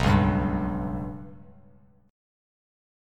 Bsus2#5 chord